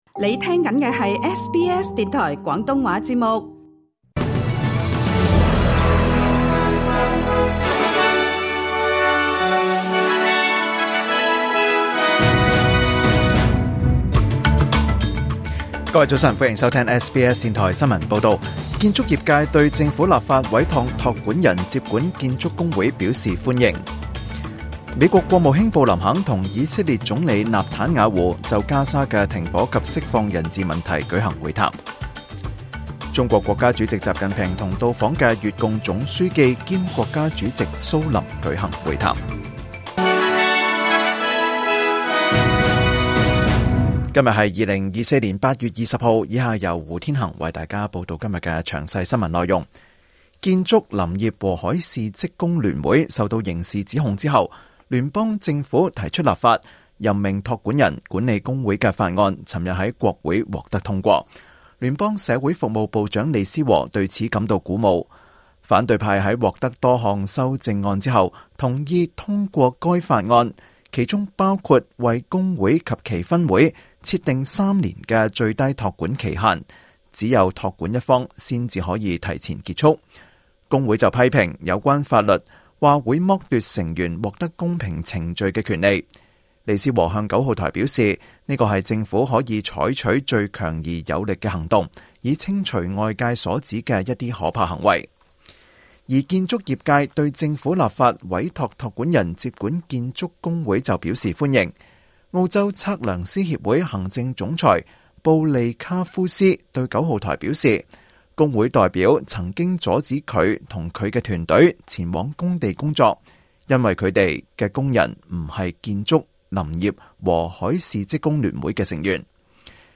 2024年8月20日SBS廣東話節目詳盡早晨新聞報道。